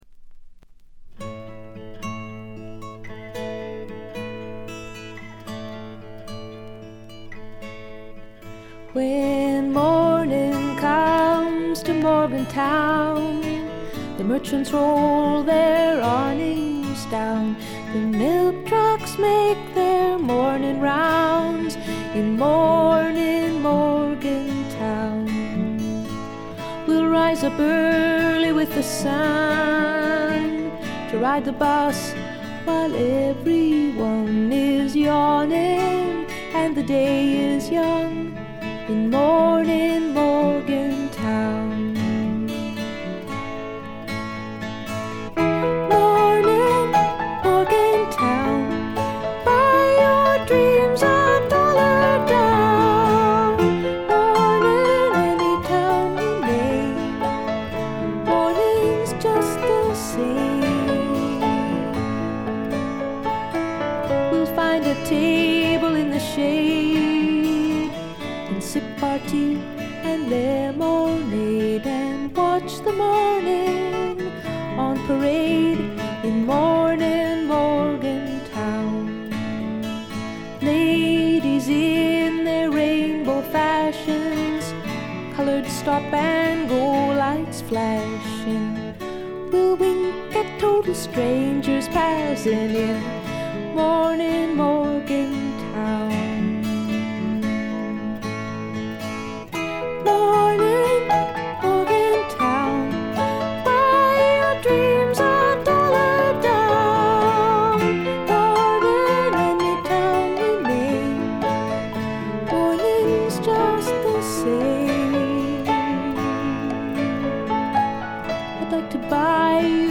軽微なチリプチ少々。散発的なプツ音が2-3箇所。
美しいことこの上ない女性シンガー・ソングライター名作。
試聴曲は現品からの取り込み音源です。
Vocals, Guitar, Piano
Recorded At - A&M Studios